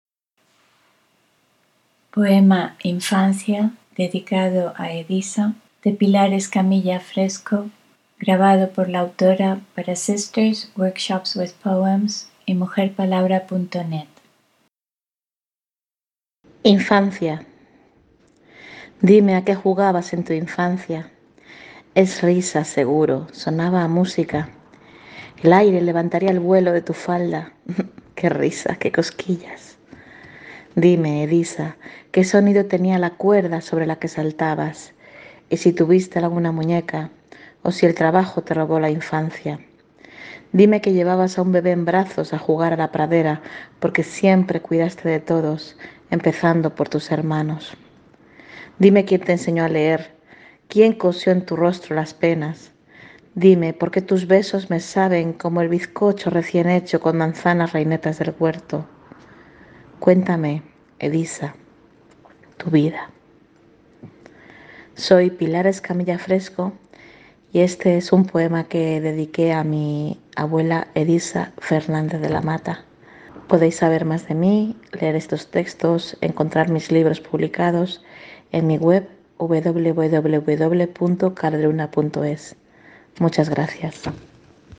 Escuchar el poema en voz de la autora